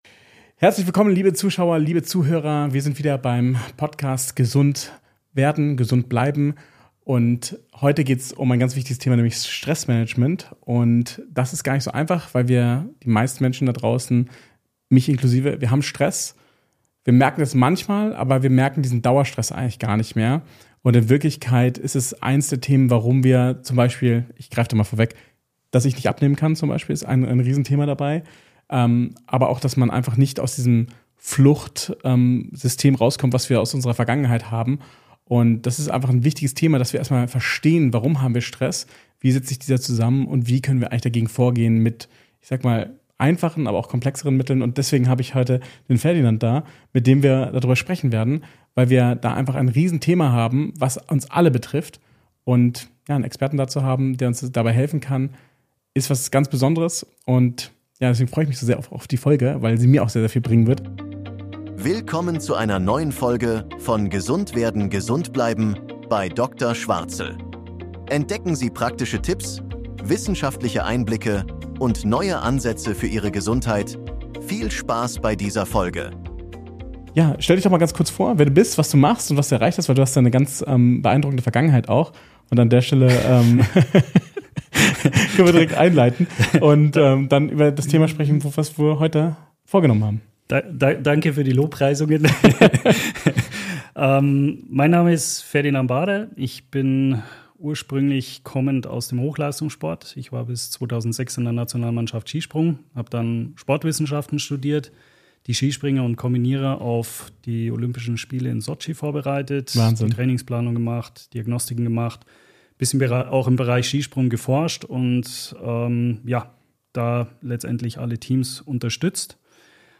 Die unsichtbare Gefahr: Wie chronischer Stress Ihre Gesundheit beeinflusst - Interview